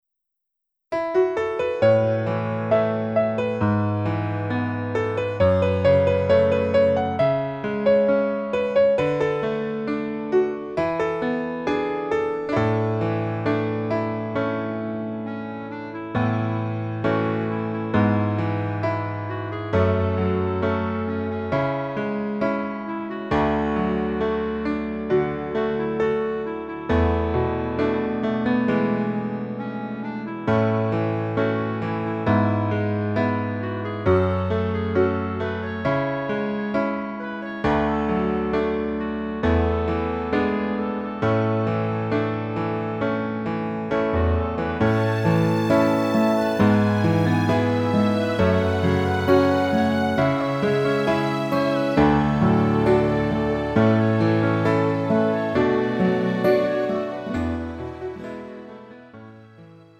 음정 (-1키)
장르 가요 구분 Pro MR